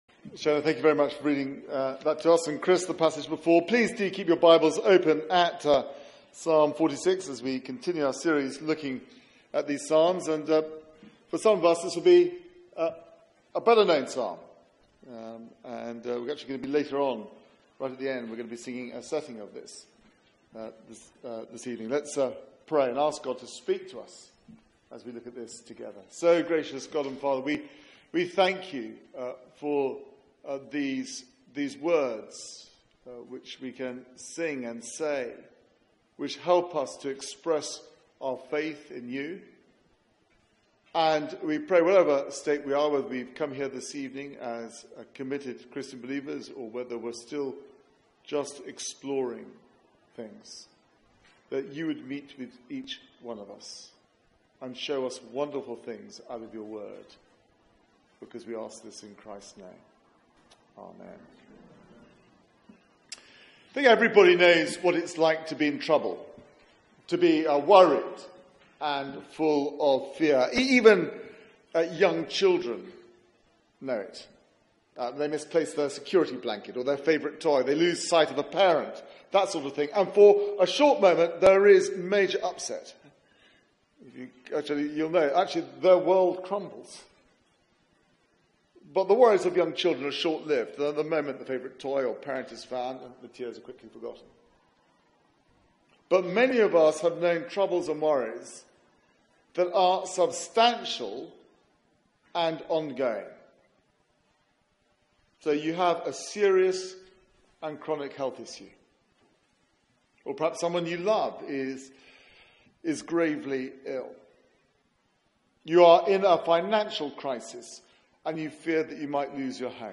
Media for 6:30pm Service on Sun 13th Aug 2017 18:30 Speaker
Summer Psalms Theme: God our refuge Sermon Search the media library There are recordings here going back several years.